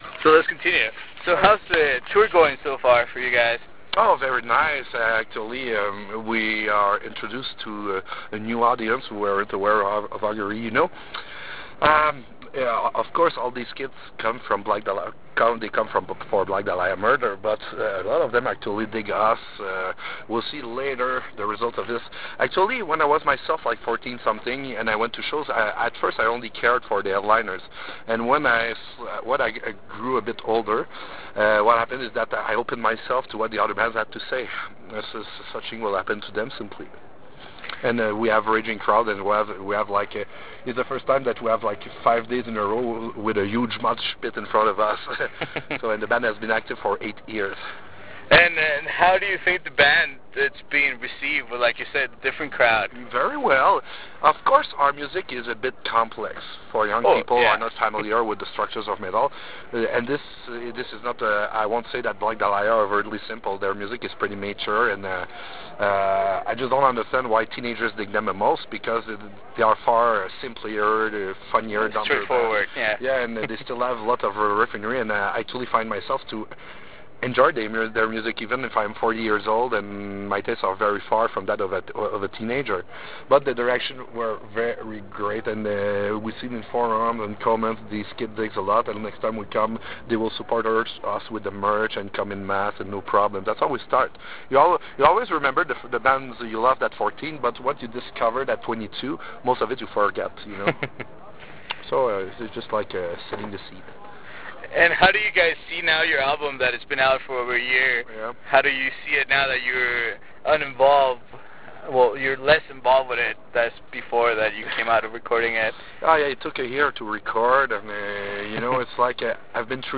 During the interview we discussed the band's touring experiences, their latest album, and what's next for the band. We also talked about the struggles before Augury gained recognition, the Canadian metal scene and music in general. To hear this interview click HERE, or select Save As and take it with you.